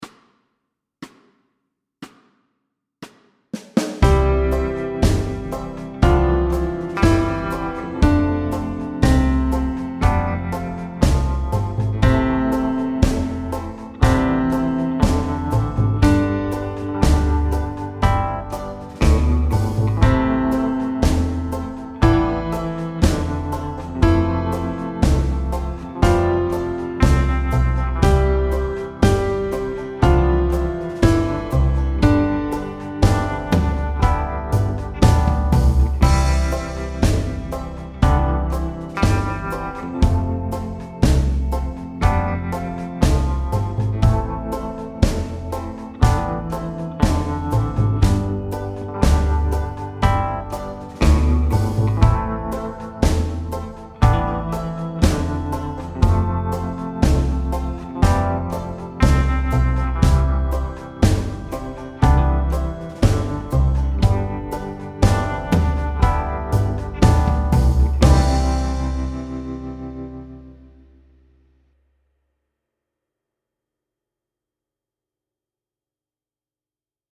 Slow C instr (demo)